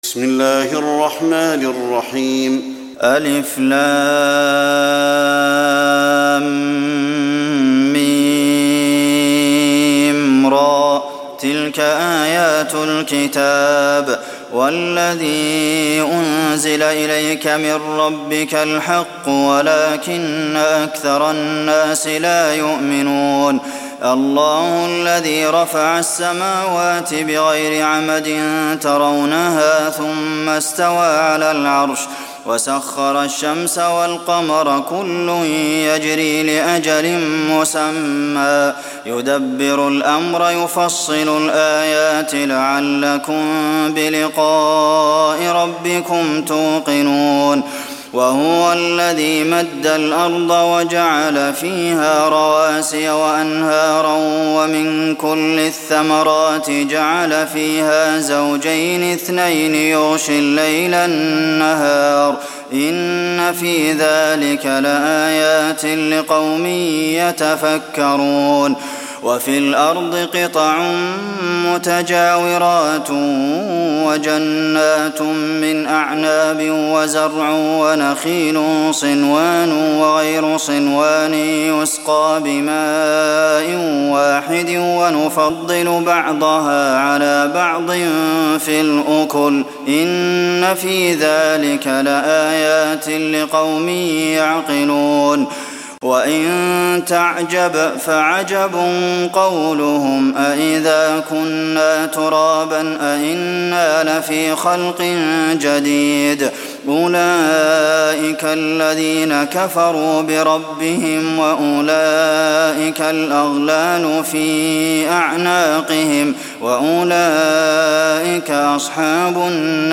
تراويح الليلة الثانية عشر رمضان 1423هـ من سورتي الرعد (1-43) و إبراهيم (1-9) Taraweeh 12 st night Ramadan 1423H from Surah Ar-Ra'd and Ibrahim > تراويح الحرم النبوي عام 1423 🕌 > التراويح - تلاوات الحرمين